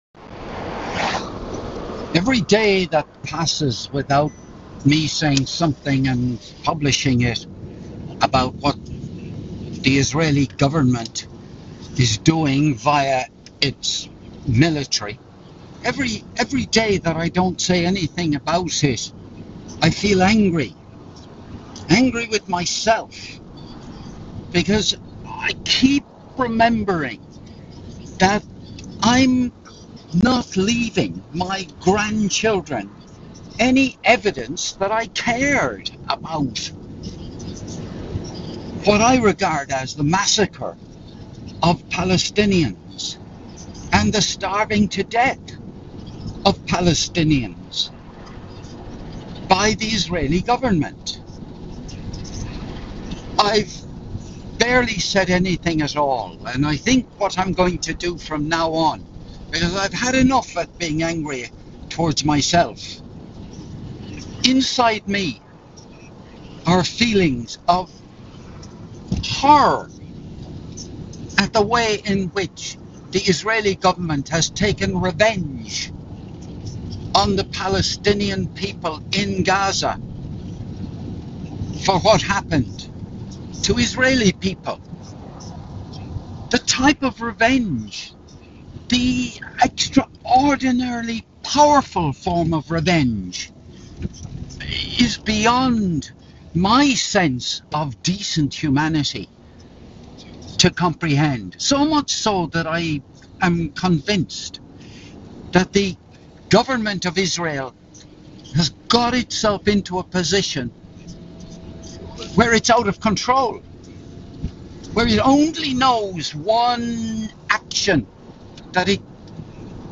This was recorded on the afternoon of Friday 25th July 2025 - while driving home from golf